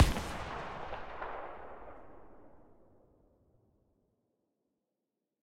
kraber_veryfar.ogg